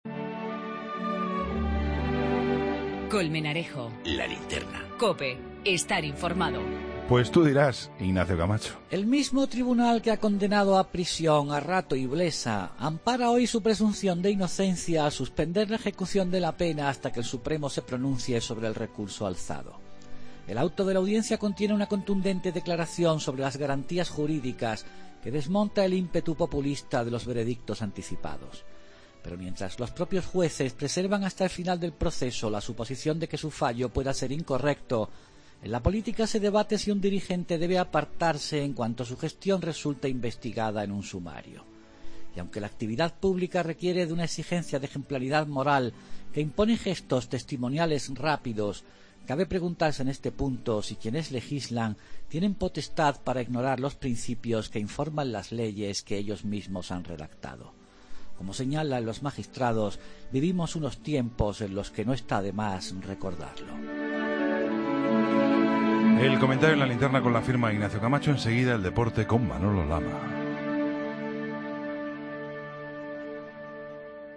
El comentario de Ignacio Camacho en 'La Linterna' de Colmenarejo